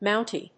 音節Mount・y 発音記号・読み方
/mάʊnṭi(米国英語)/